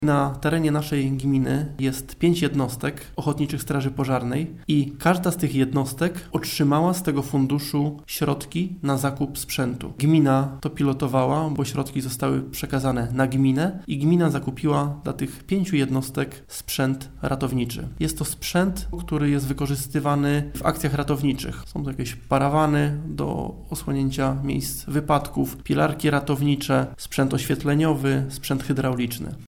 – Strażacy z naszej gminy także otrzymali pieniądze z tego funduszu – informuje Marcin Reczuch, wójt gminy Dąbie.